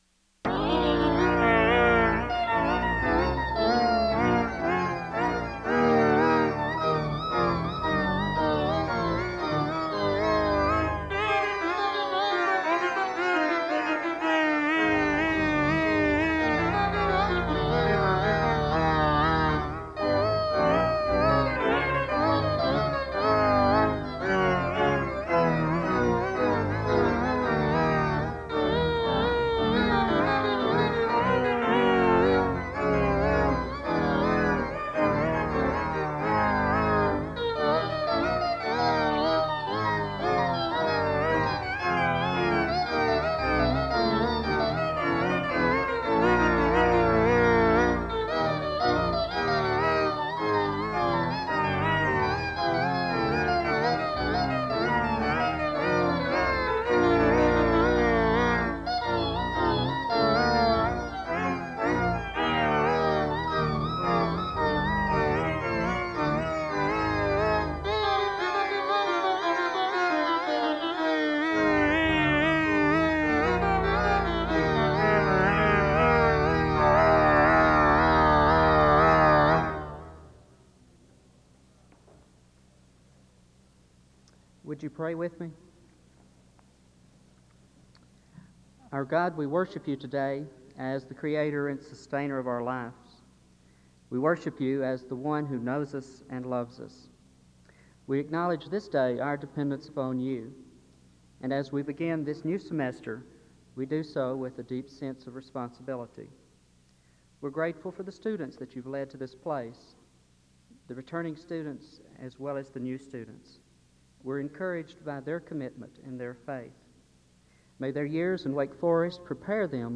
Introductory music is played (0:00-1:24). The service begins with prayer (1:24-2:44). Further music is played (2:44-5:23).
There is a reading of 2 Chronicles 7:12-14 and James 5:7-8 (8:25-10:10).
A final presentation of music is given (38:08-42:30). Convocation is closed with prayer (42:30-43:55).